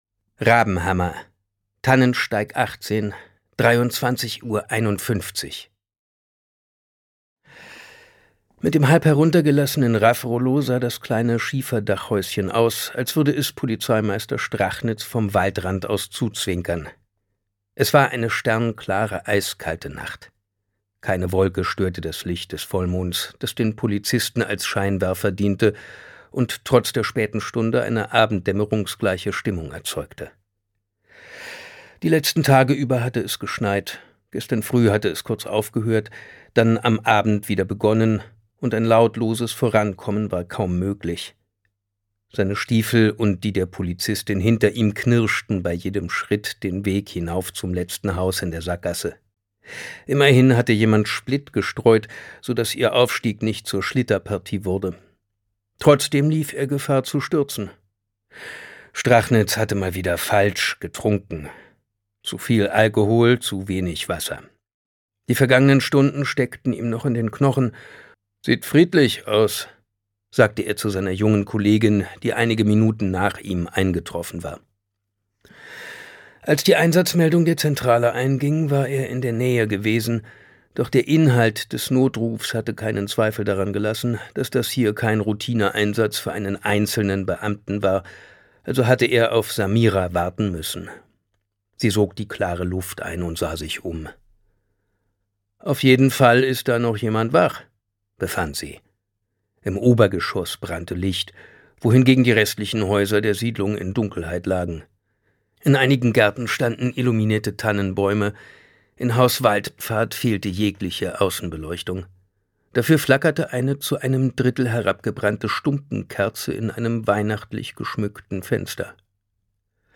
Das Kalendermädchen Thriller Sebastian Fitzek (Autor) Simon Jäger (Sprecher) Audio Disc 2024 | 1.